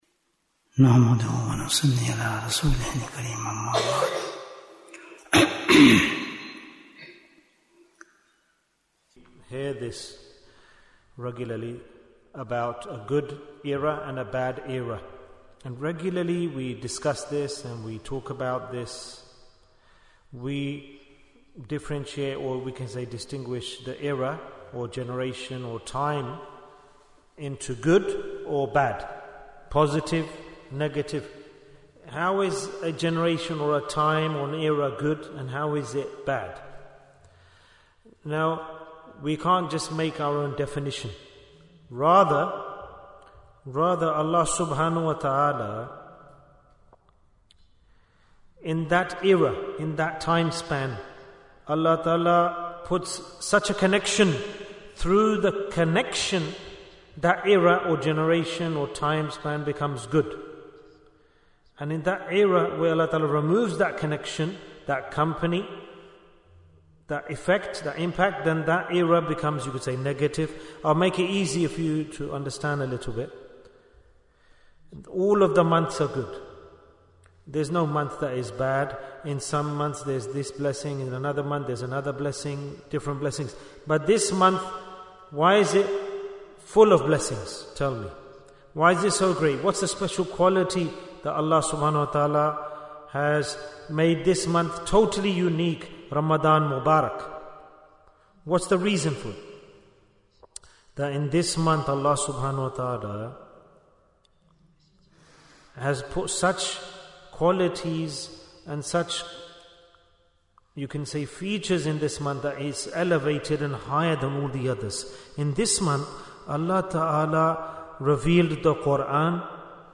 Jewels of Ramadhan 2025 - Episode 9 - The Light of Connection Bayan, 57 minutes8th March, 2025